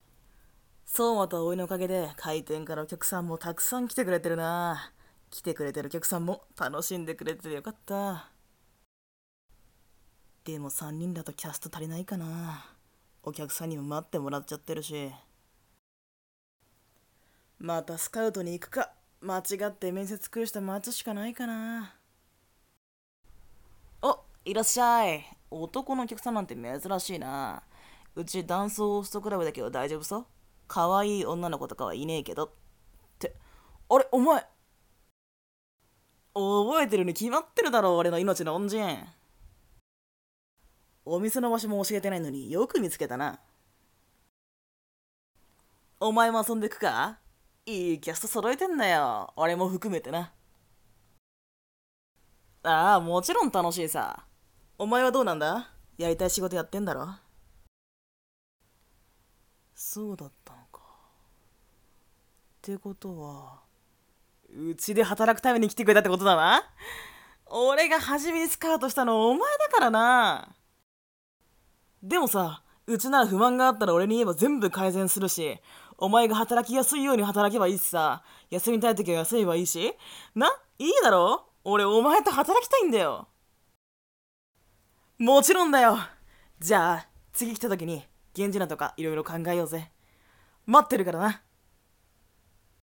景品交換声劇🌼